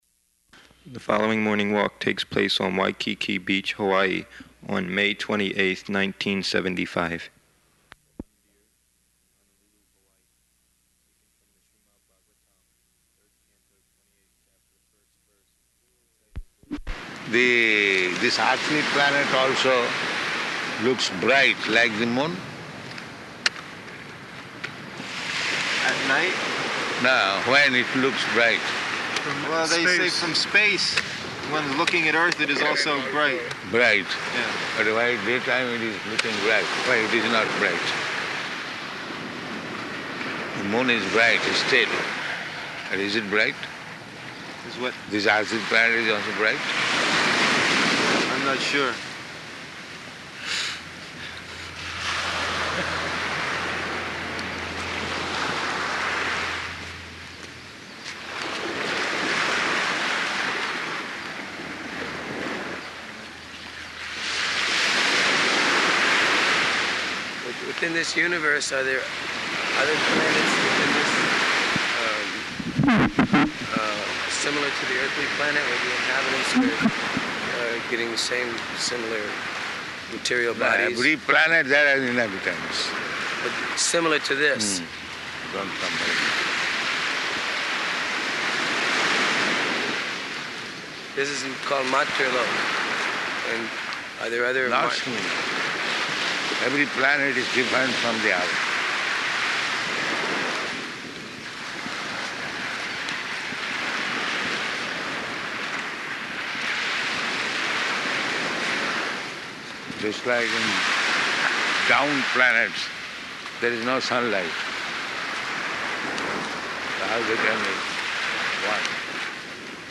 Morning Walk on Waikiki Beach
Type: Walk
Location: Honolulu